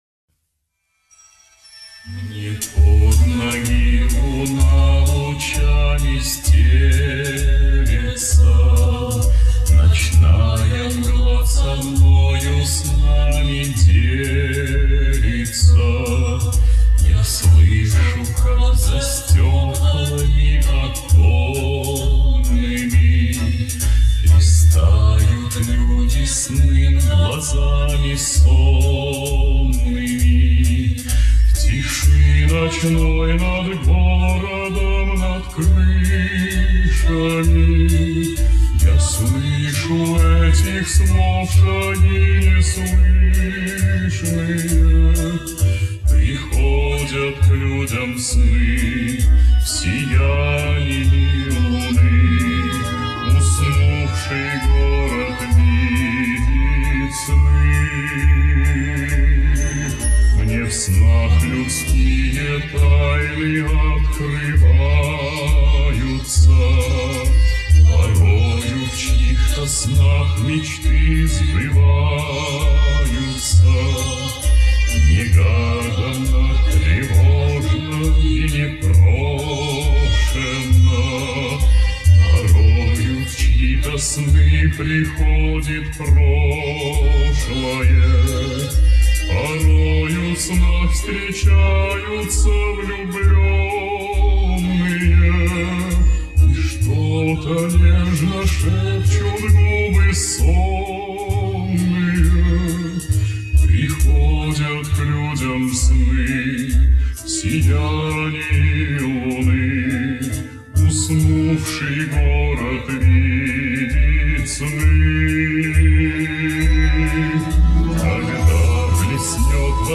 Восстановление